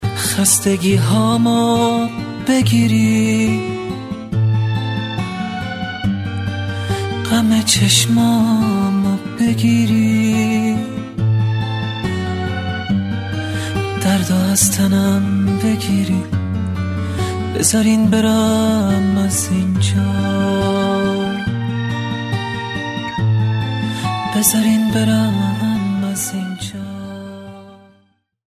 با کلام و ملایم